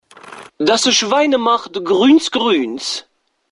Das Schwein macht gru╠ênz.mp3